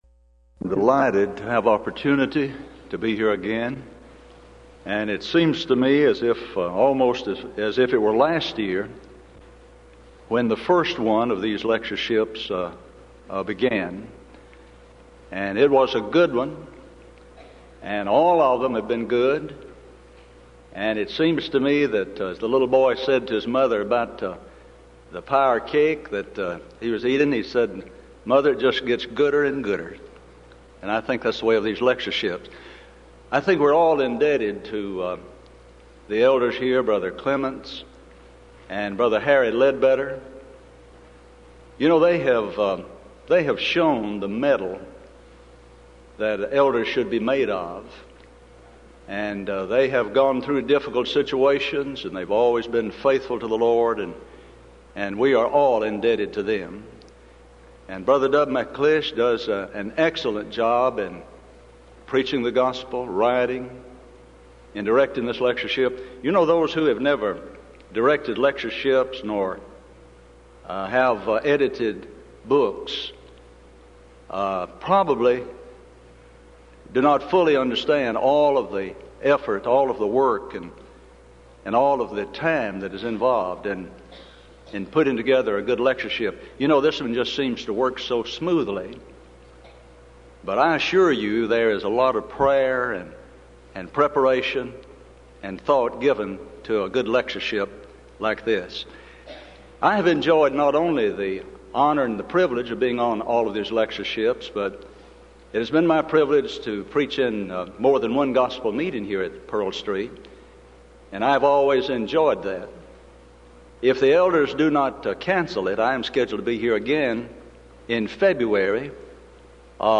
Event: 1994 Denton Lectures
lecture